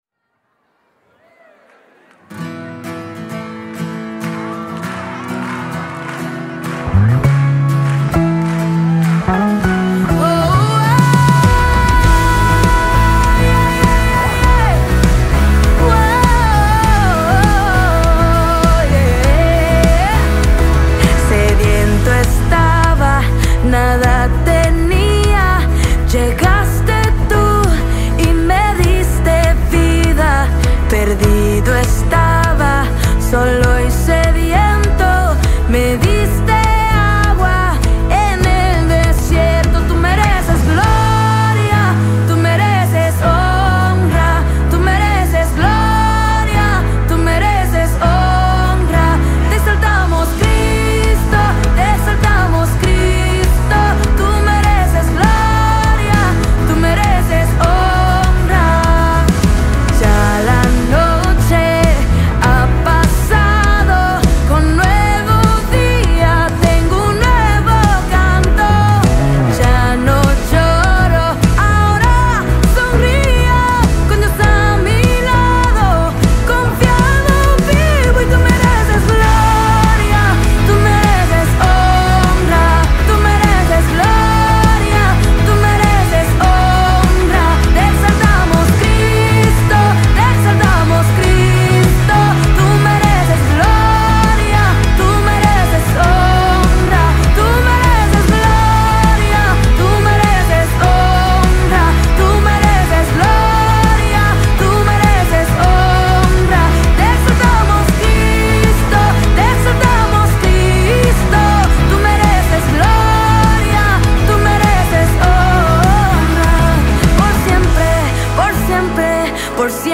7 просмотров 33 прослушивания 0 скачиваний BPM: 100